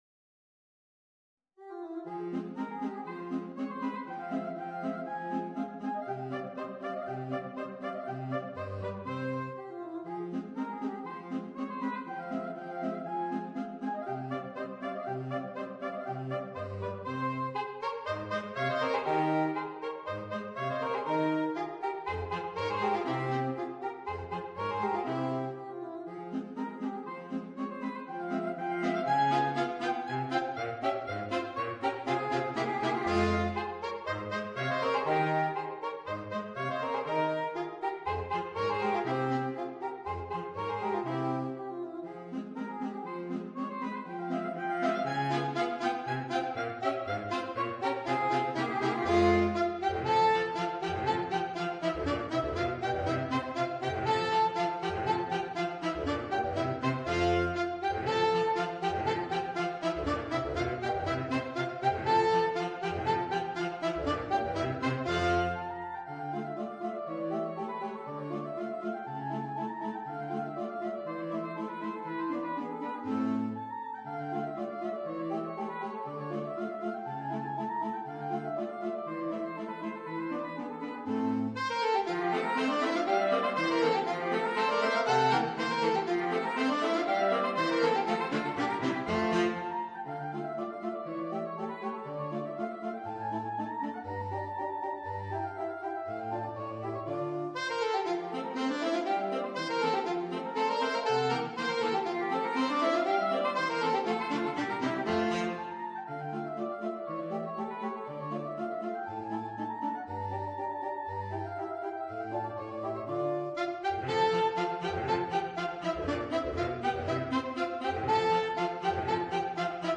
per quartetto di sassofoni